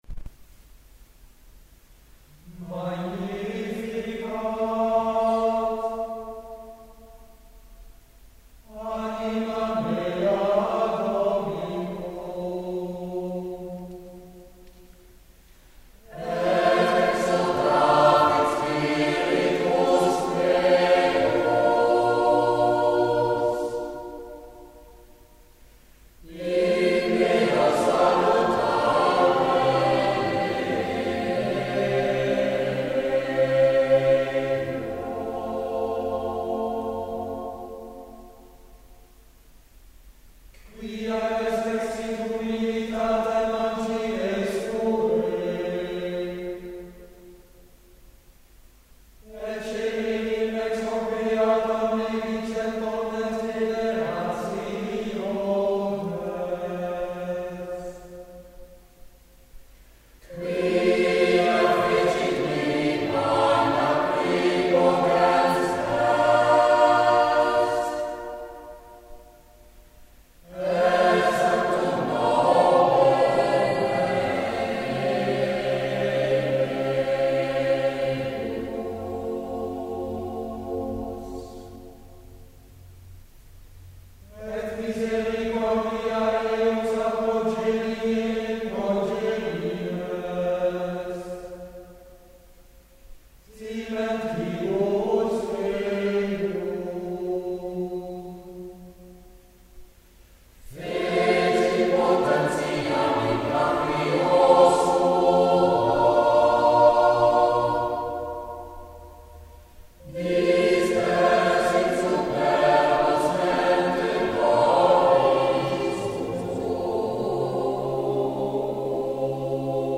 Het repertoire van I Cantatori bestaat vooral uit polyfone muziek uit de Renaissance en vroeg barok, aangevuld met muziek uit latere perioden geinspireerd op de Renaissance.